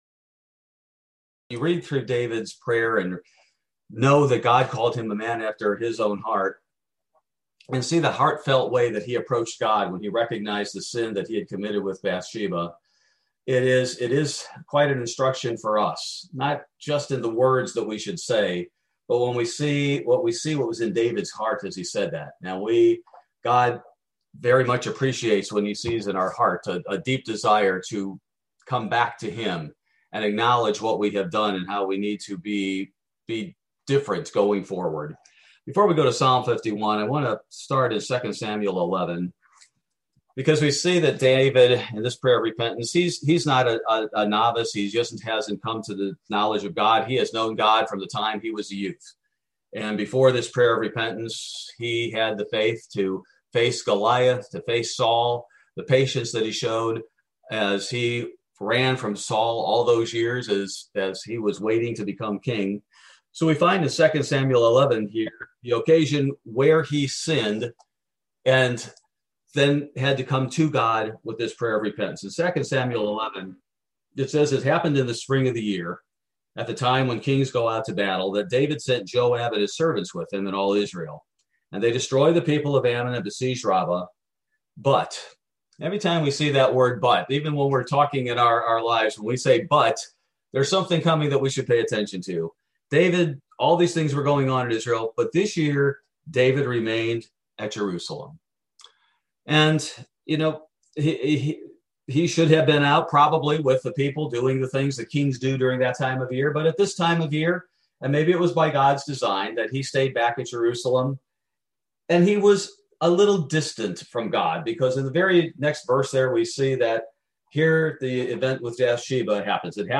Bible Study: March 24, 2021